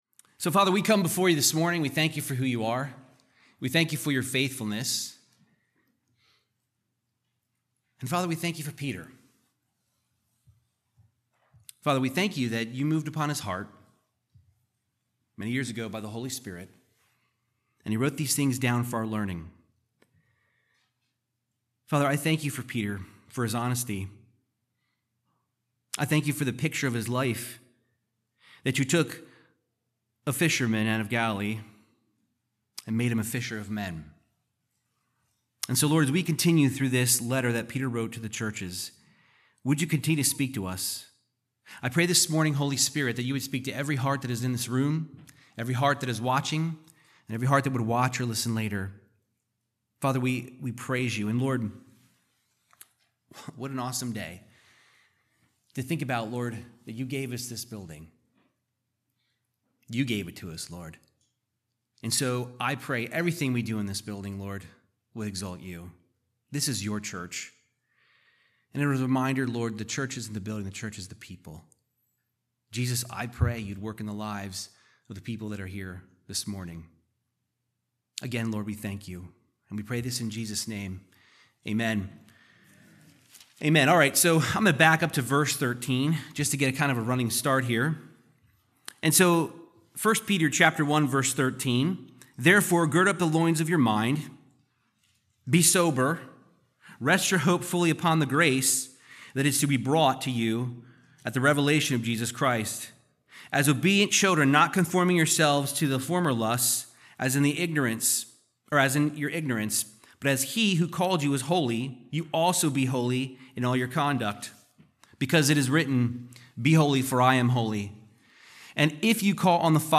Verse by verse Bible teaching of 1 Peter 1:22-2:2 discussing the incorruptible eternal salvation of a Christian by Jesus the word of God